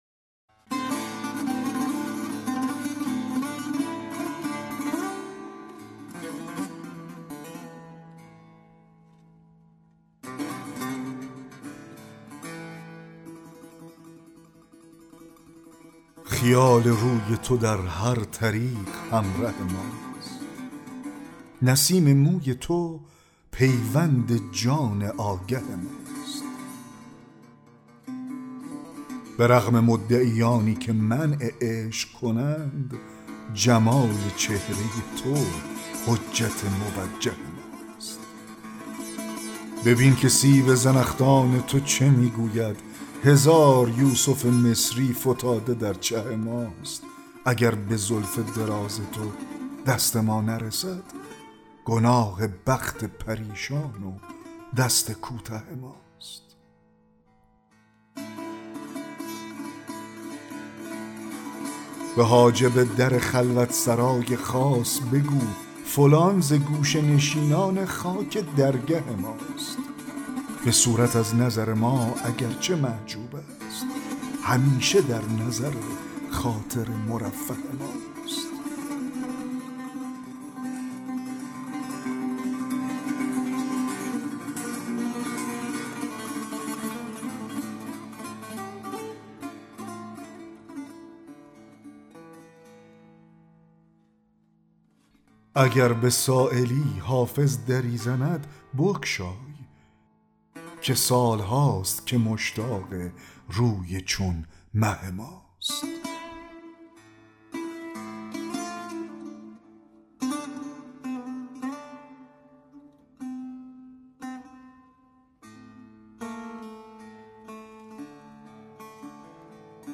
دکلمه غزل 23 حافظ
دکلمه-غزل-23-حافظ-خیال-روی-تو-در-هر-طریق-همره-ماست.mp3